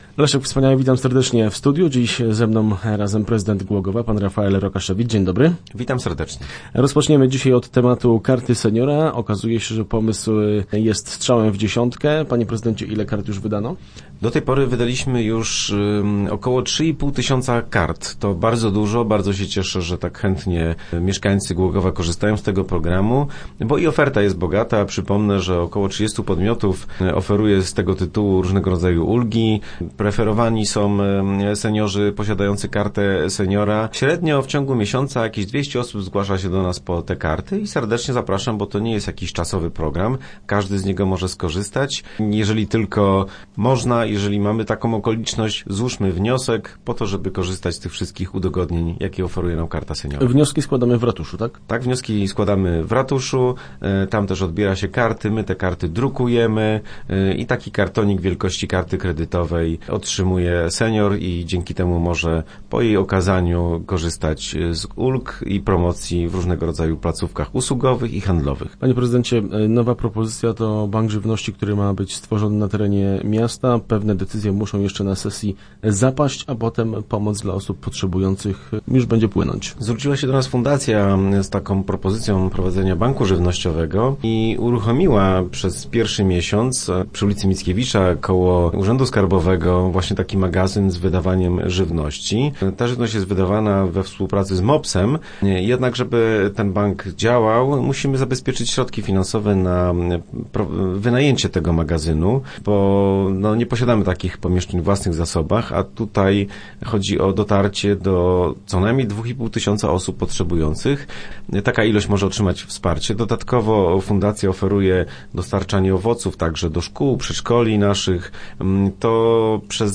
Start arrow Rozmowy Elki arrow Średnio 200 osób na miesiąc
Ale nie tylko na ten temat rozmawialiśmy w środę z prezydentem Głogowa Rafaelem Rokaszewiczem.